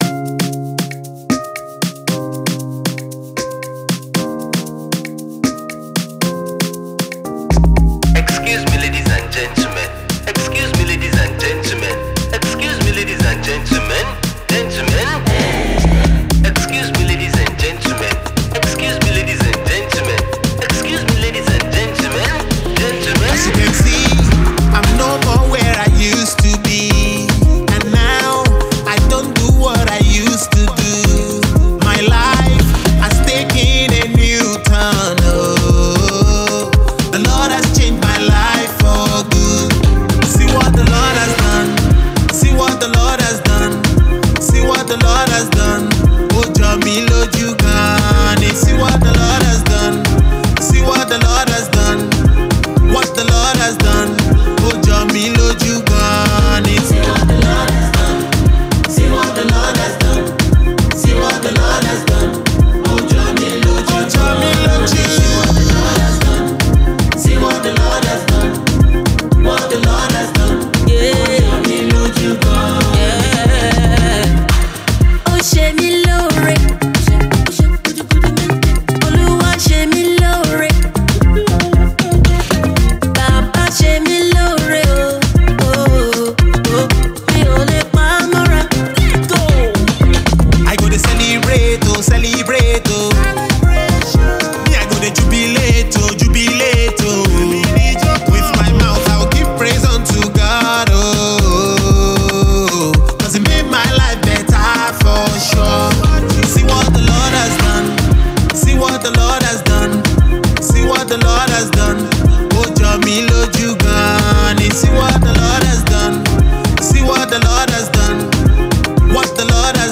a contemporary Christian singer